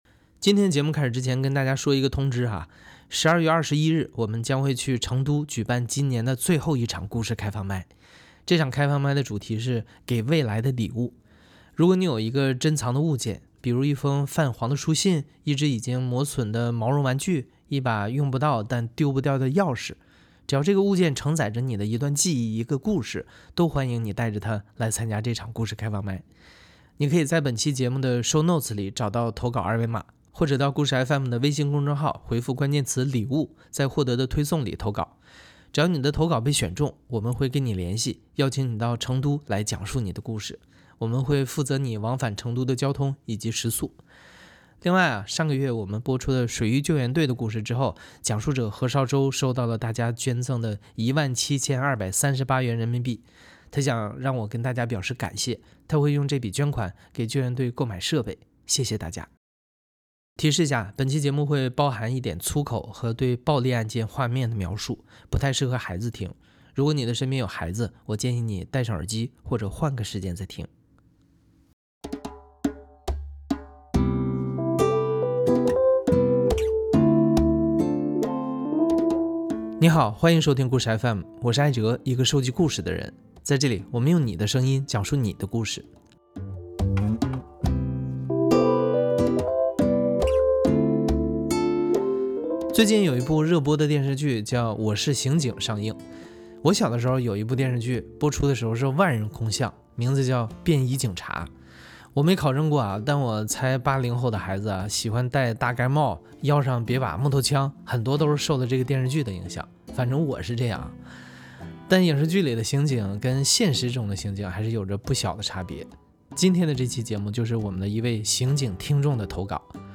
——————————————————— 提示一下，本期节目会包含一点粗口和对暴力案件画面的描述，不太适合孩子听，如果你的身边有孩子，我建议你戴上耳机或者换个时间再听。
故事FM 是一档亲历者自述的声音节目。